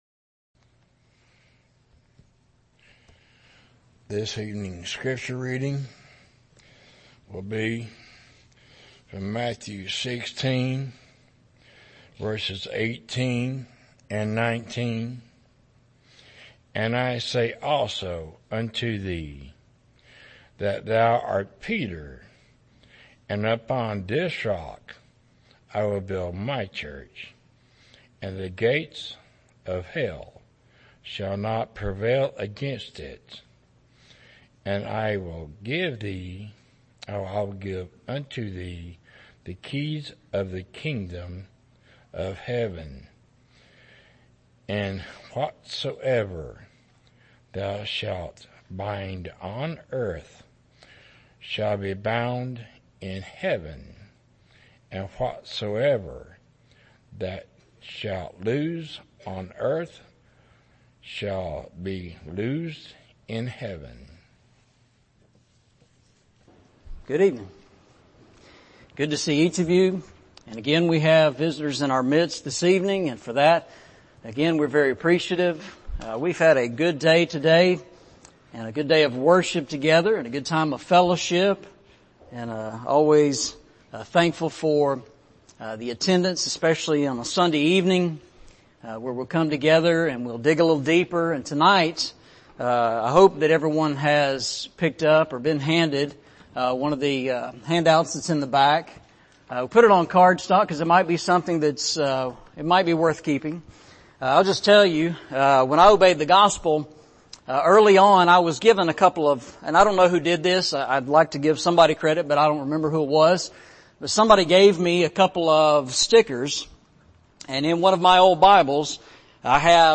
Eastside Sermons Service Type: Sunday Evening Preacher